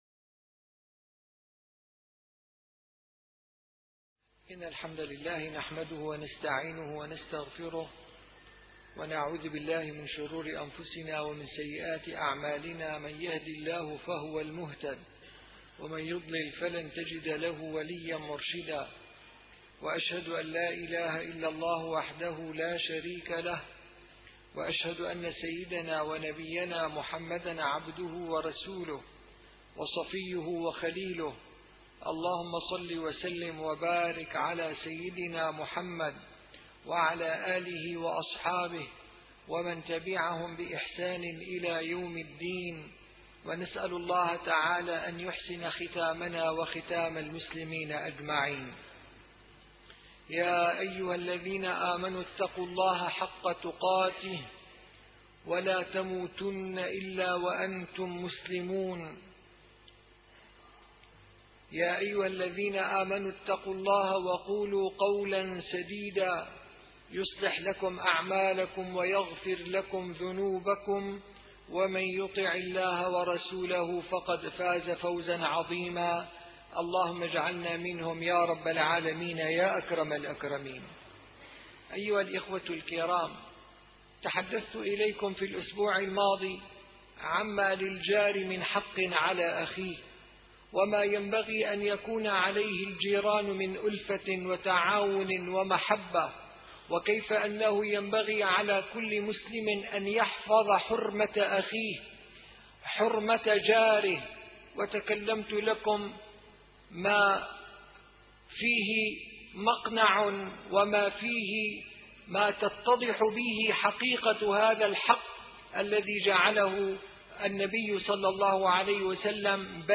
- الخطب - حق المسلم على المسلم